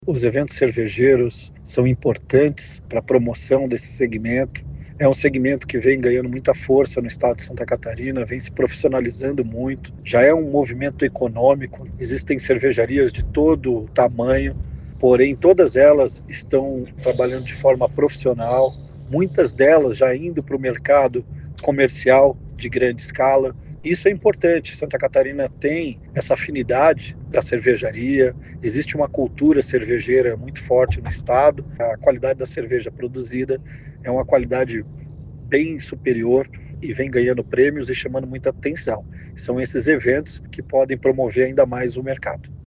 Segundo o secretário do Turismo, Evandro Neiva, o segmento vem ganhando muita força em Santa Catarina já que o estado tem uma cultura cervejeira:
SECOM-Sonora-secretario-do-Turismo-1.mp3